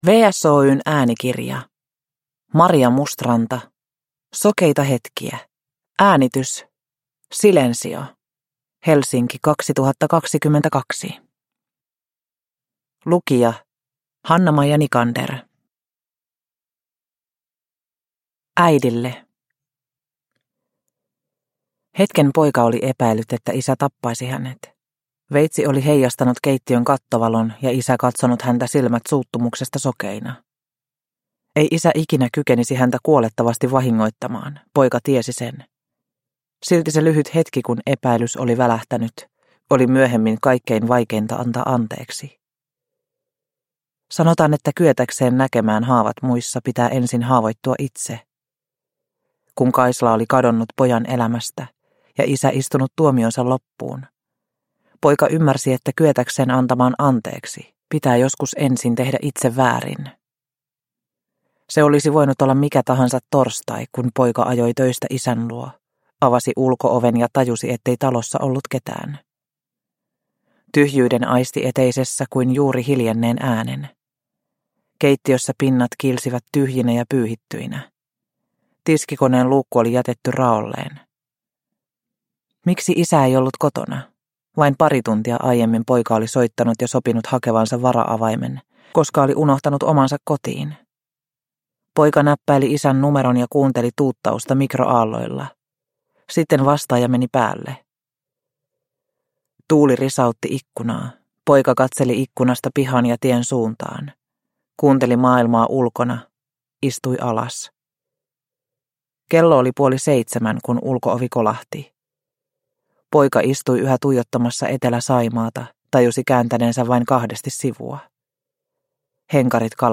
Sokeita hetkiä – Ljudbok – Laddas ner